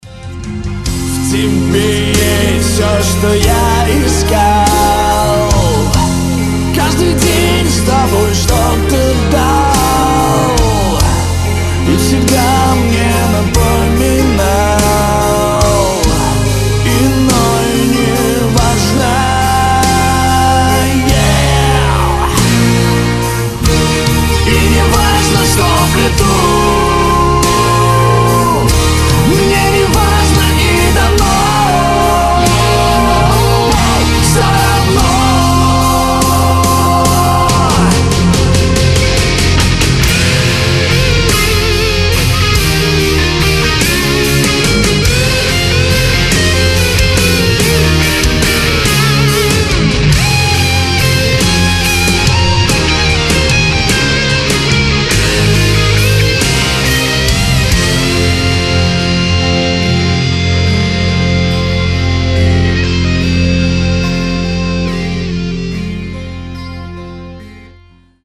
• Качество: 320, Stereo
Cover
электрогитара
романтичные
ballads
heavy Metal
эпичные
рок-баллада